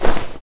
pocketrussle.mp3